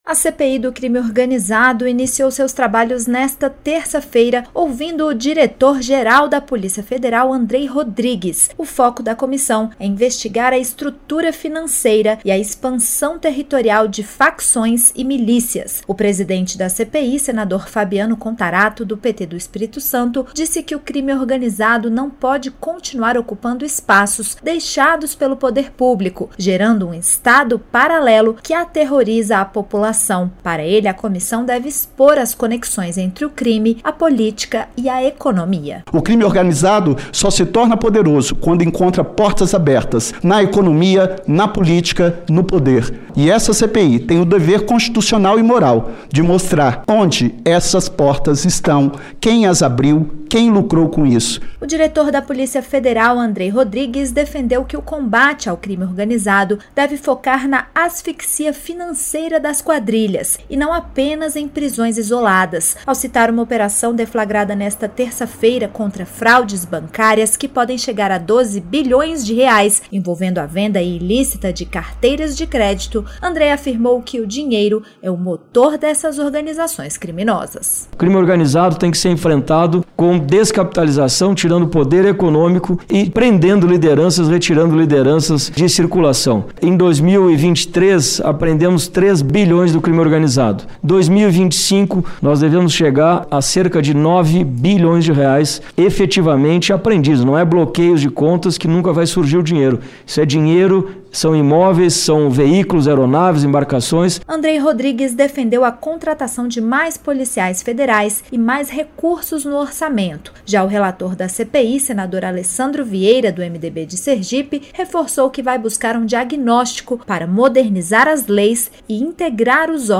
Diretor da PF aponta fraudes bilionárias na CPI do Crime Organizado